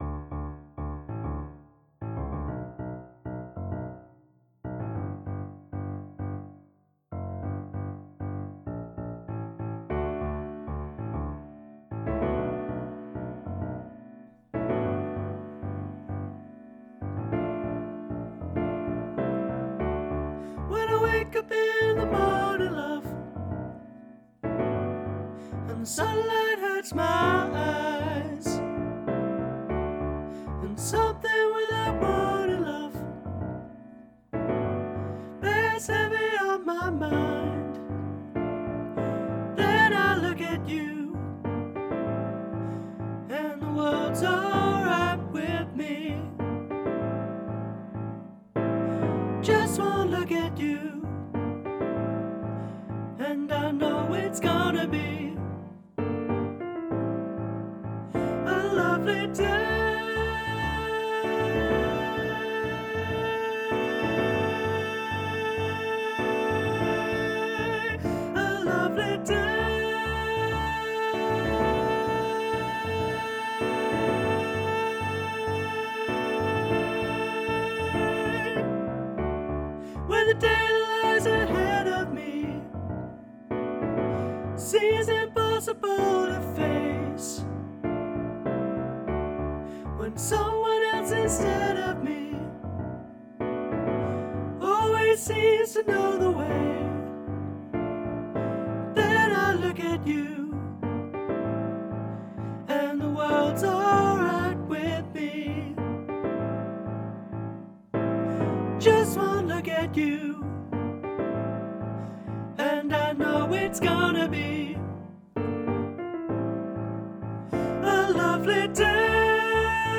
This Bill Withers classic has been arranged by Sam Gilliatt in a fun 2 part arrangement.
LovelyDay-altos.mp3